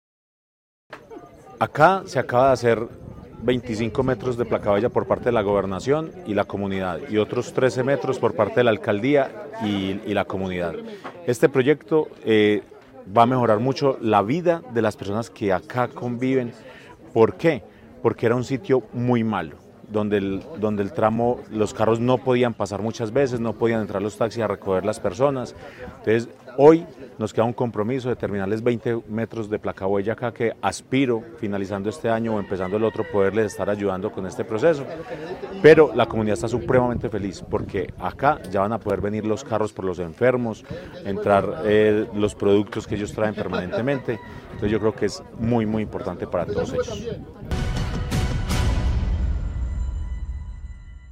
Carlos Alberto Riveros, alcalde de Chinchiná